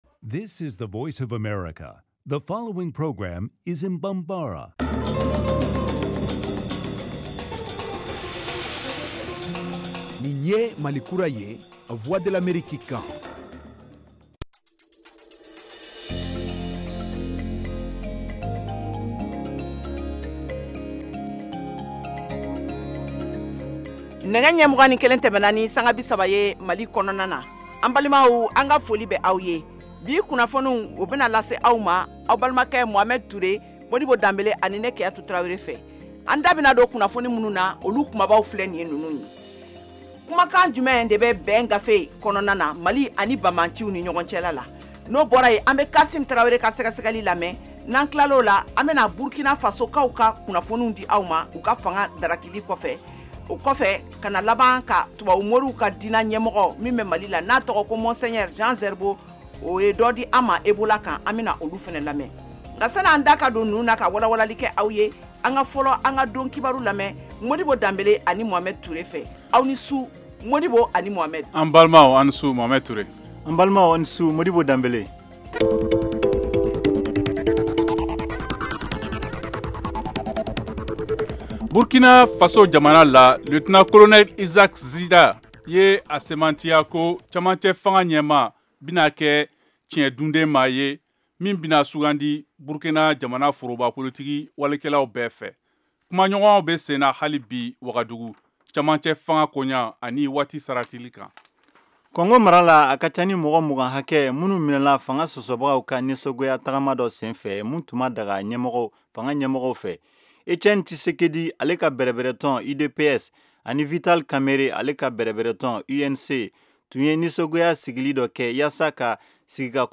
en direct de Washington